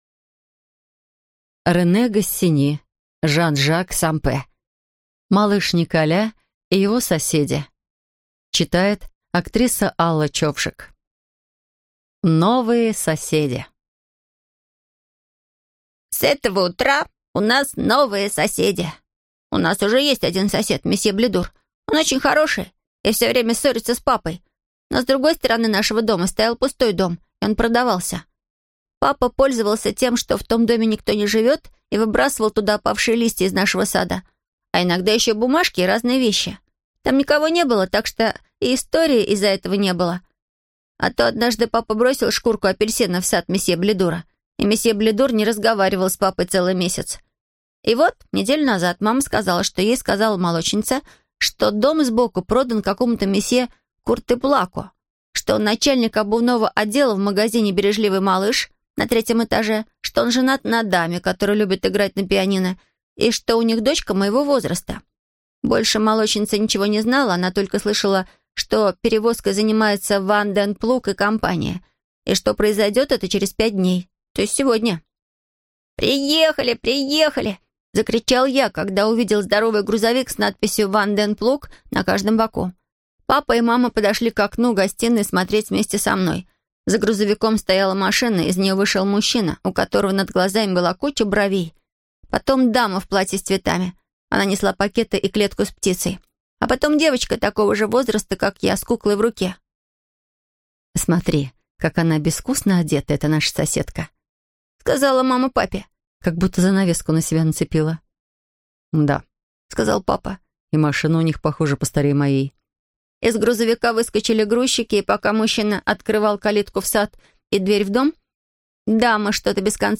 Аудиокнига Малыш Николя и его соседи | Библиотека аудиокниг